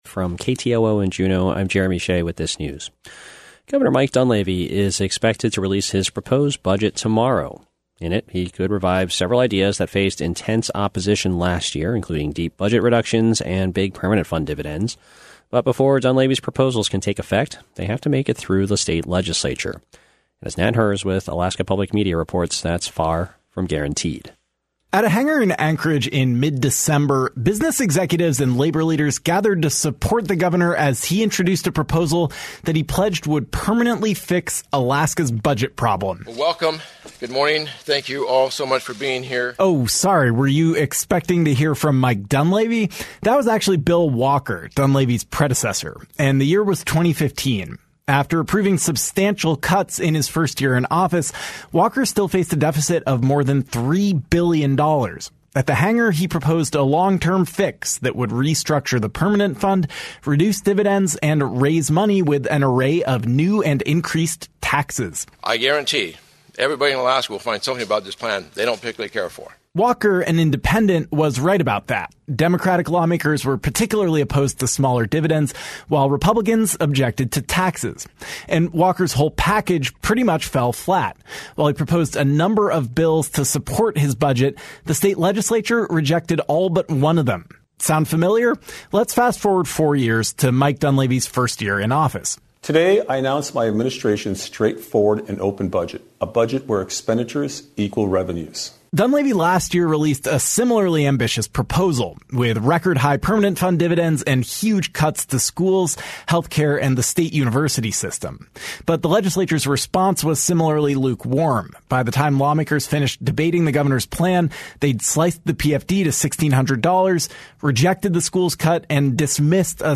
Newscast - Tuesday, Dec. 10, 2019